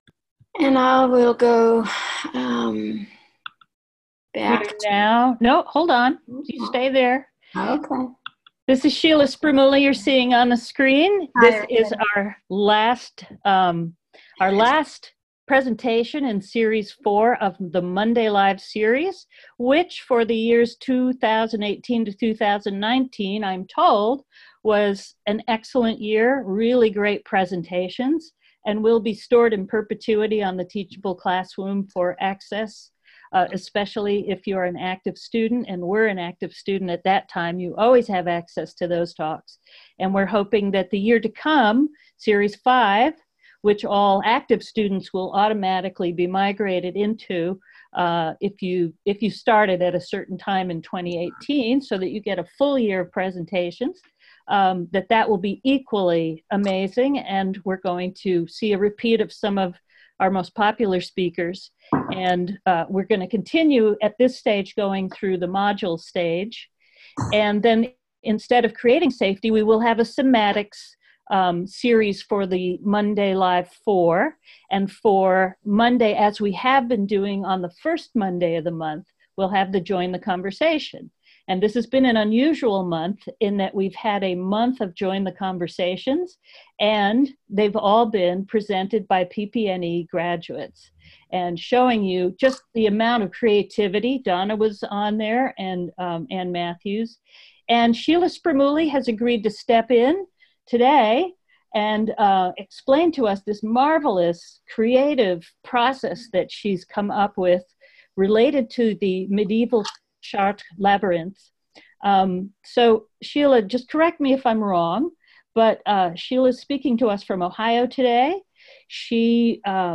PPNE students receive Monday LIVE! free, and everyone else is welcome to join for a year of interactive talks (44 total) for the low price of $179.